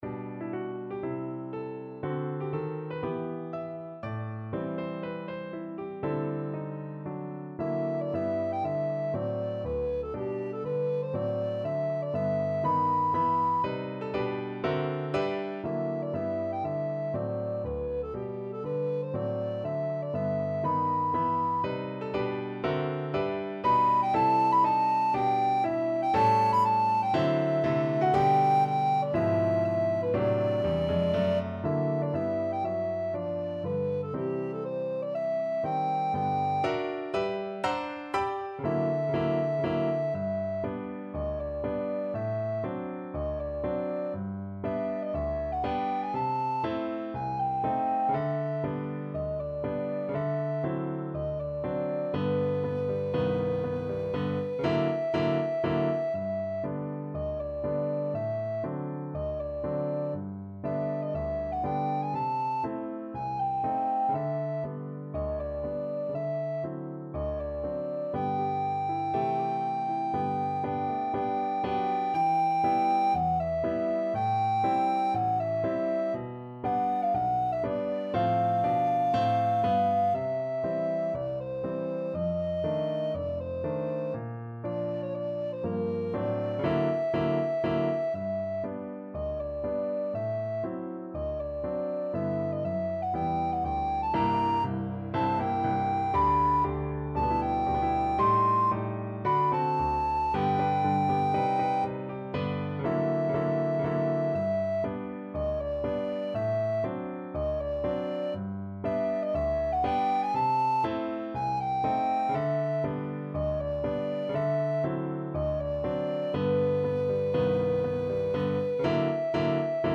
~ = 120 Moderato
Classical (View more Classical Alto Recorder Music)